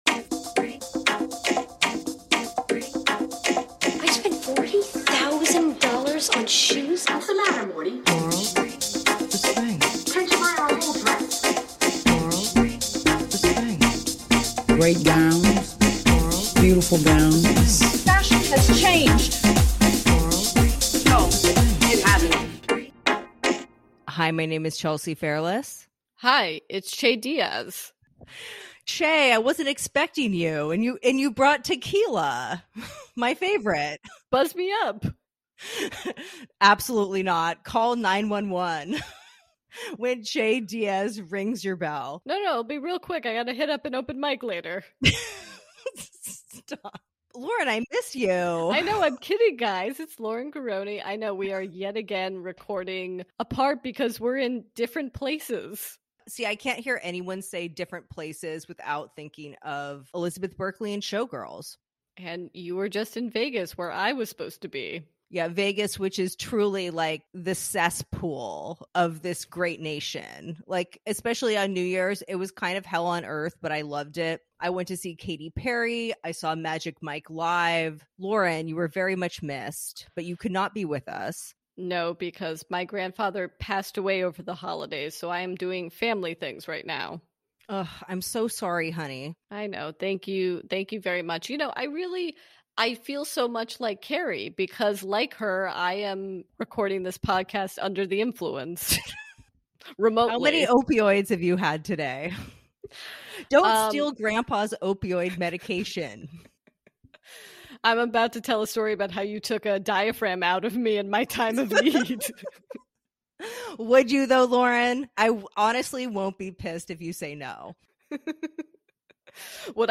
On this week's episode rewatch, the ladies catch up on their New Years, answer some calls, and discuss this MAJOR episode. Topics include: Carrie's hip surgery, why it wasn't caused by her lifelong heel addiction, Anthony's first brunch, the introduction of Rock, Miranda's drunken Amazon habit, Carrie's inability to hold her pee, THAT sex scene, our appraisal of the series, and SO MUCH MORE!